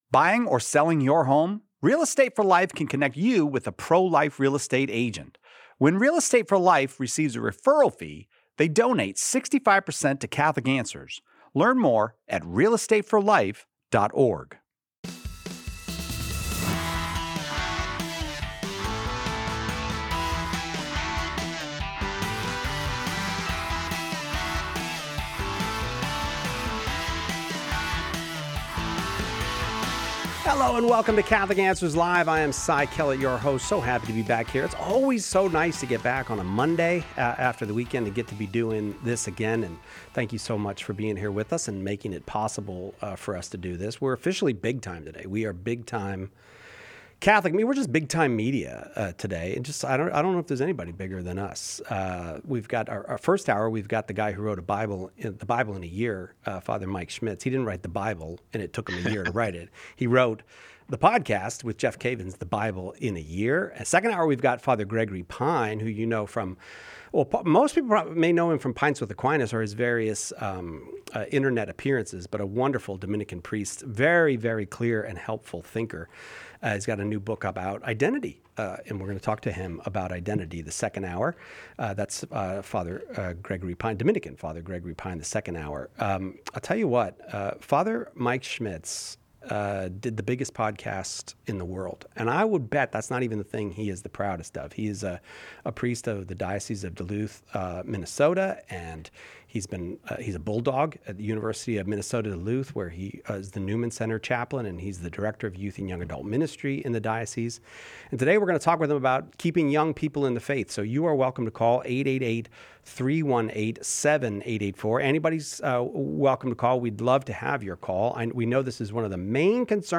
This episode explores essential tips for nurturing faith in youth, along with strategies for catechizing high schoolers beyond traditional Bible studies. We also address challenges like maintaining faith in split households and navigating relationships when commitment seems scarce. Tune in for a thoughtful discussion on these pressing topics.